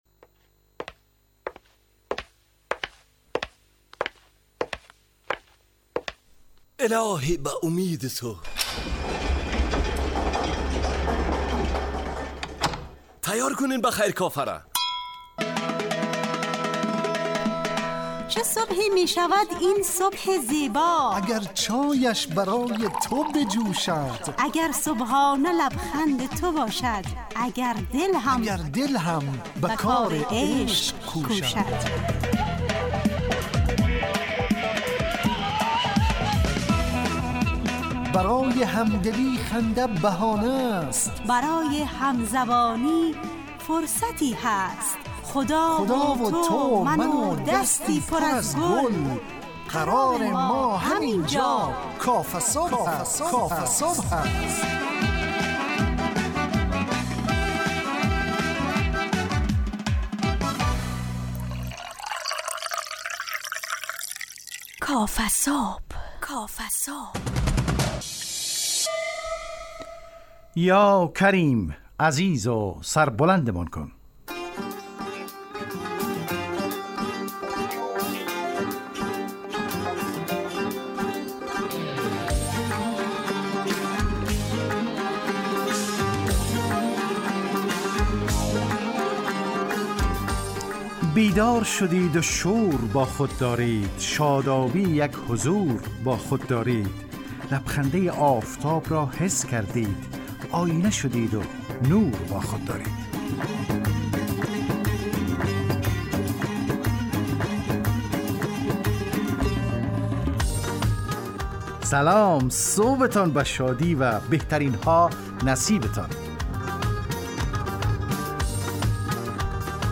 کافه‌صبح – مجله‌ی صبحگاهی رادیو دری با هدف ایجاد فضای شاد و پرنشاط صبحگاهی همراه با طرح موضوعات اجتماعی، فرهنگی و اقتصادی جامعه افغانستان با بخش‌های کارشناسی، نگاهی به سایت‌ها، گزارش، هواشناسی، صبح جامعه و صداها و پیام‌ها شنونده‌های عزیز